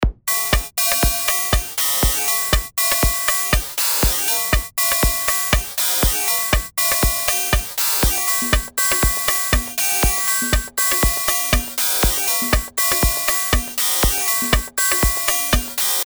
Dabei können dann beispielsweise solche futuristischen Lo-Fi-Hi-Hats entstehen:
Das klingt schon reichlich speziell und etwas zersetzend.